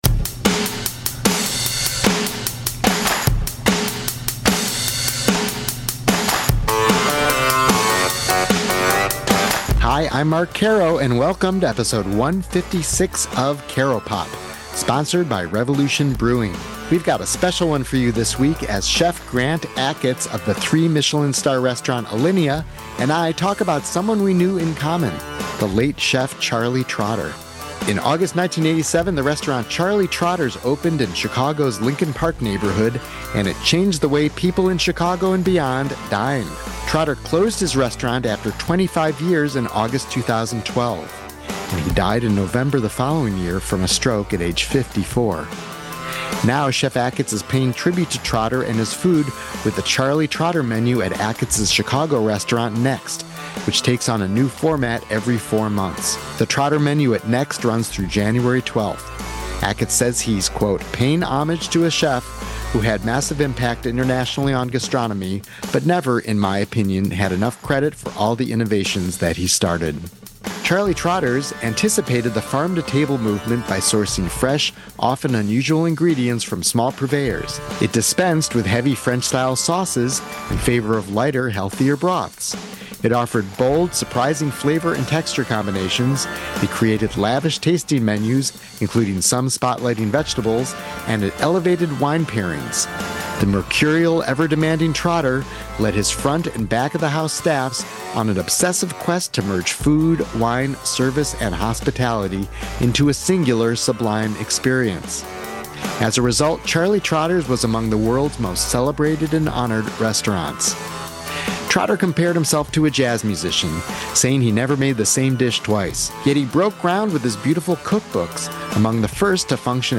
There may be nothing more inspiring and entertaining than relaxed, candid conversations among creative people.